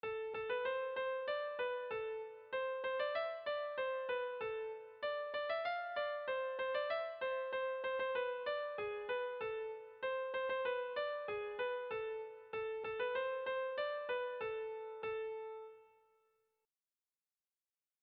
Erlijiozkoa
Eibar < Debabarrena < Gipuzkoa < Euskal Herria
ABD